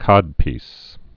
(kŏdpēs)